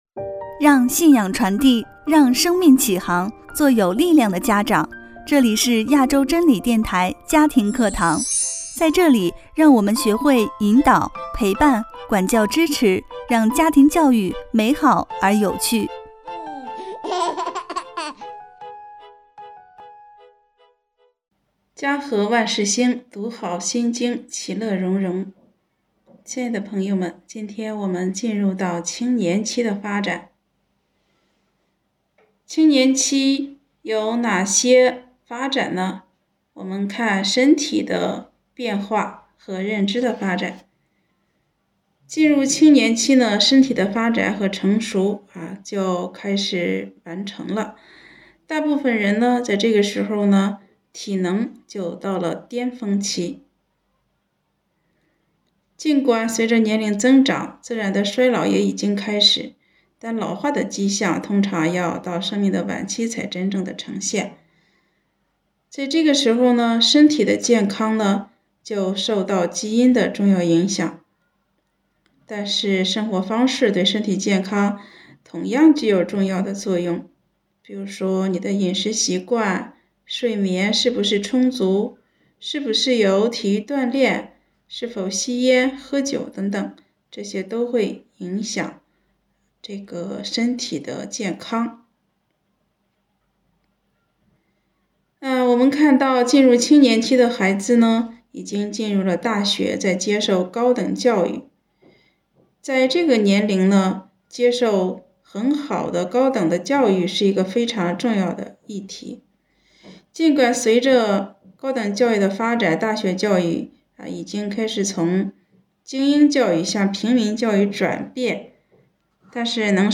第八讲 青年期的发展（一）